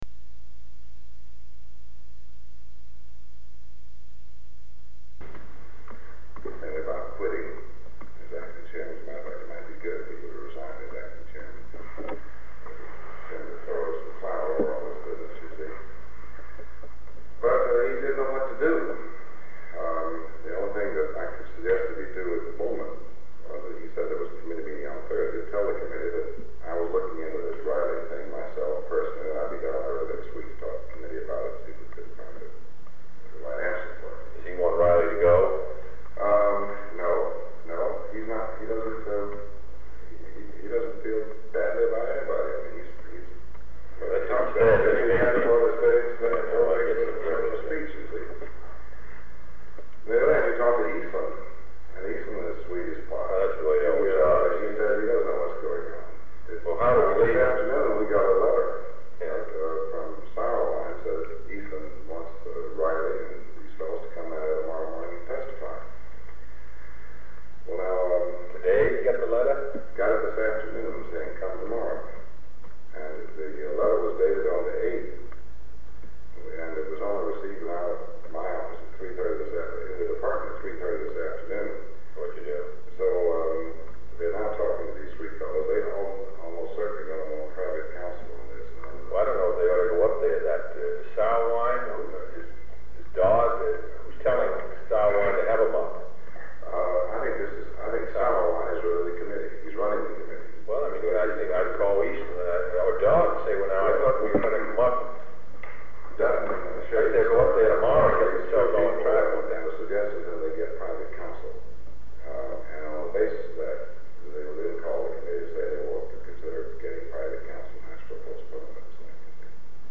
Sound recording of a brief meeting held on November 12, 1963, between President John F. Kennedy and Secretary of State Dean Rusk.
This sound recording has been excerpted from Tape 121/A57, which contains additional sound recording(s) preceding and following this one.